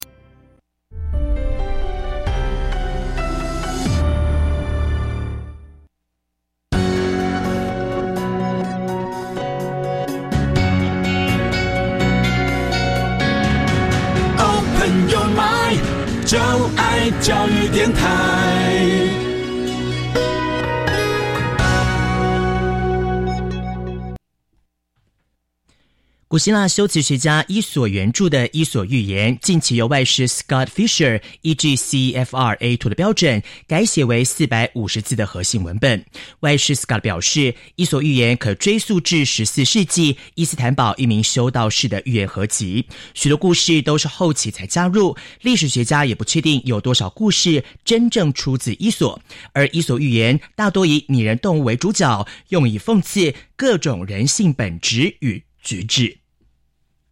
每冊書均錄製有MP3，由專業英語老師朗誦故事全文，幫助從內文與習題中練習聽力和口語能力。